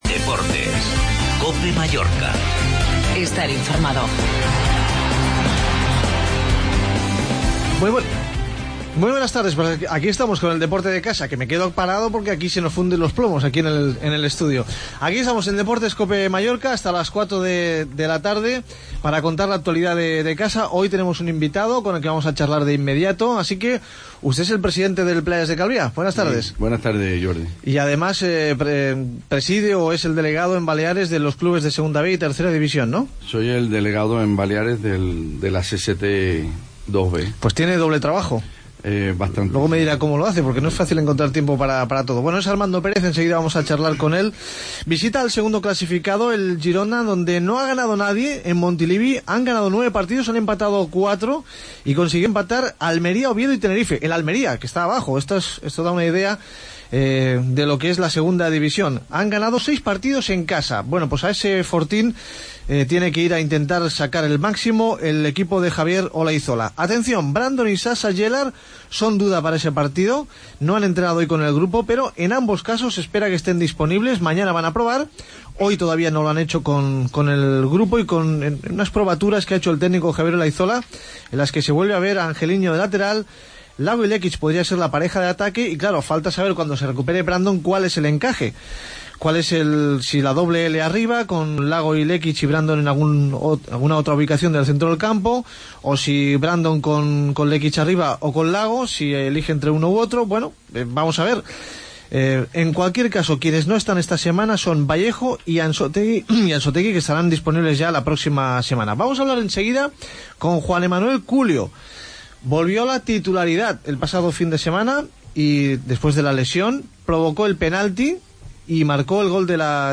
Entrevistamos al jugador del Mallorca Culio, ¿qué opina sobre que se diga que hay jugadores que no juegan por imposición?